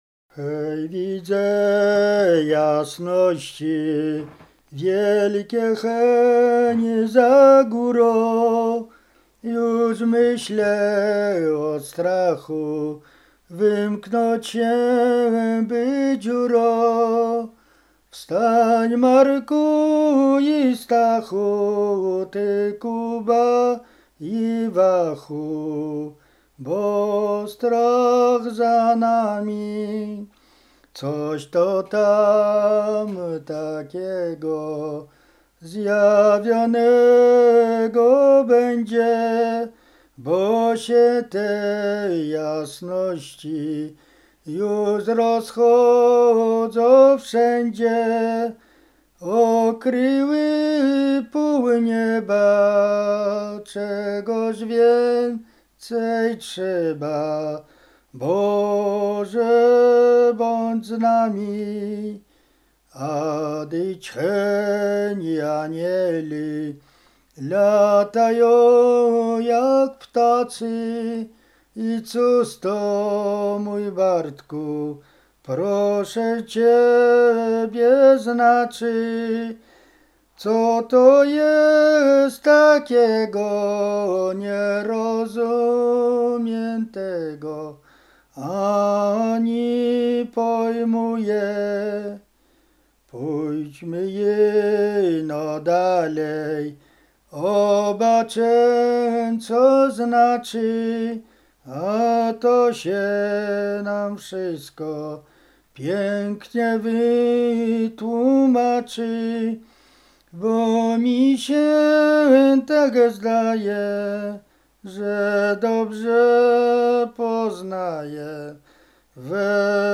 Roztocze
Kolęda
kolędowanie kolędy pastorałki